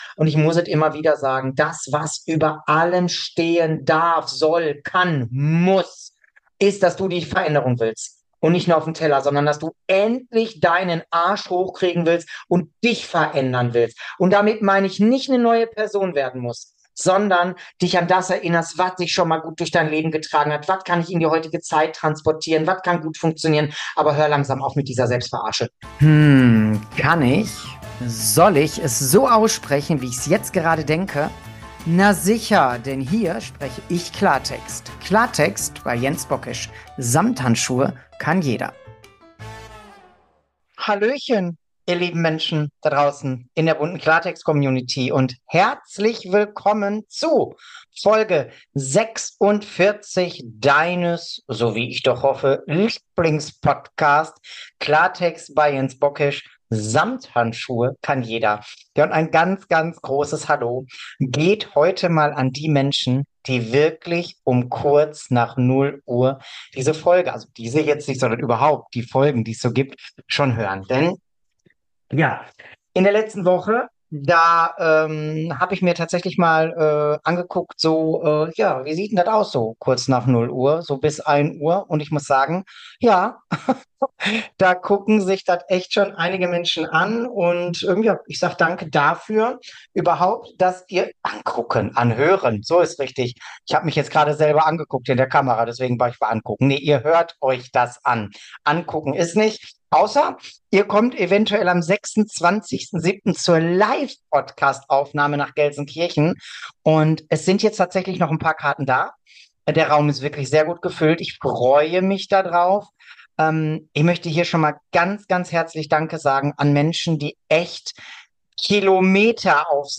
Warum du dir mit Perfektionismus selbst im Weg stehst, was passiert, wenn du nur für andere abnehmen willst, und wieso du mit „einfach weniger essen“ nichts löst – all das erfährst du hier. Kein Schönreden, kein Blabla – sondern direkte Ansagen mit Herz, Verstand und 'nem Schuss Ruhrpott.